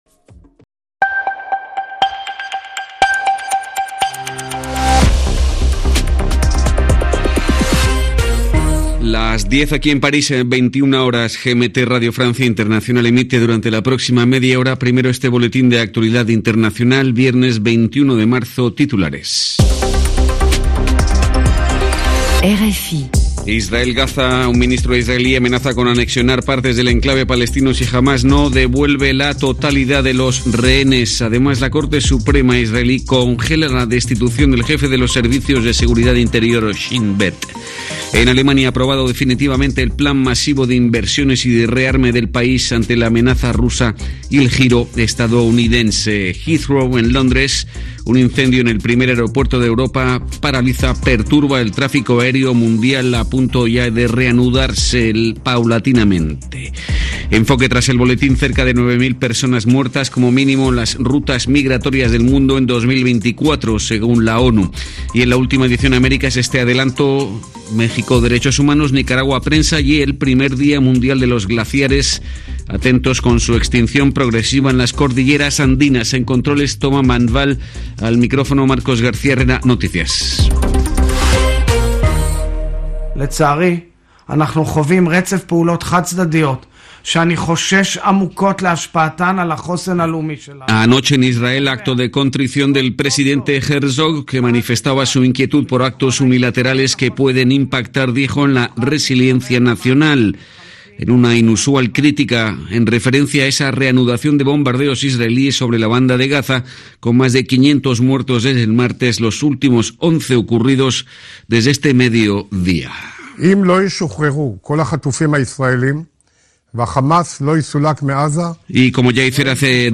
Informativo 21/03 21h00 GMT